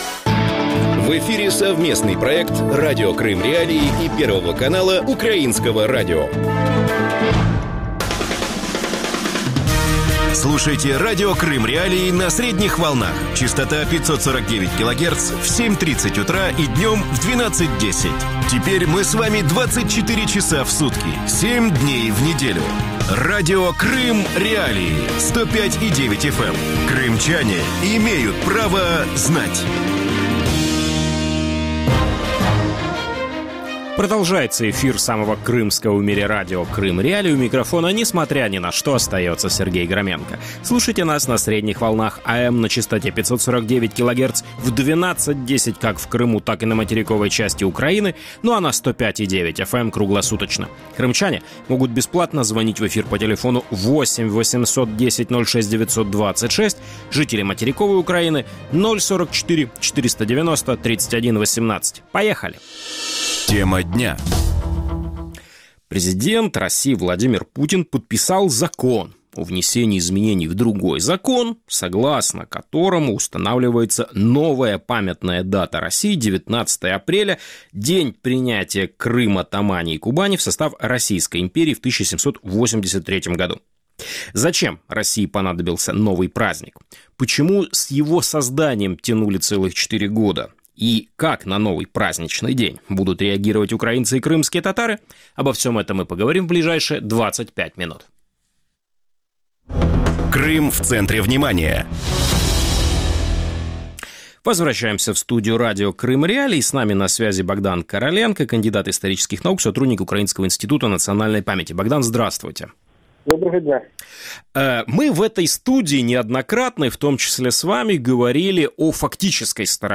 ток-шоу